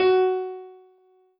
piano-ff-46.wav